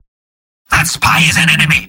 Robot-filtered lines from MvM. This is an audio clip from the game Team Fortress 2 .
{{AudioTF2}} Category:Spy Robot audio responses You cannot overwrite this file.
Spy_mvm_cloakedspyidentify06.mp3